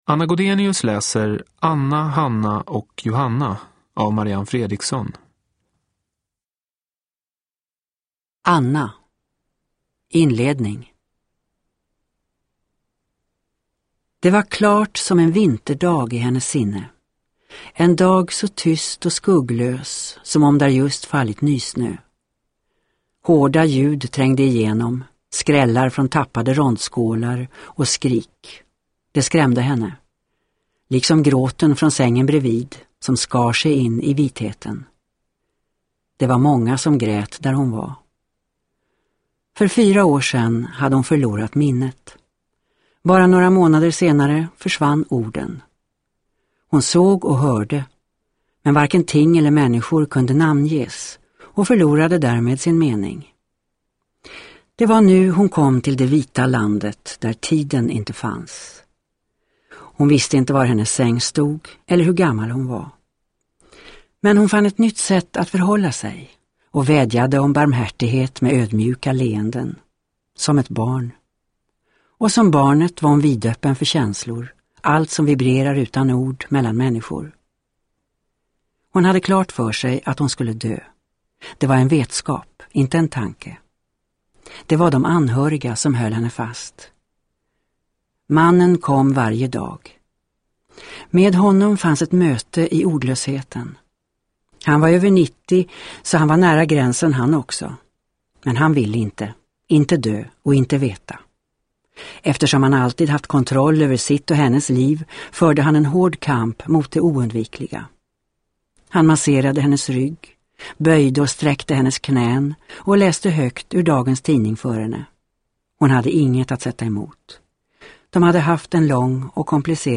Anna, Hanna och Johanna (ljudbok) av Marianne Fredriksson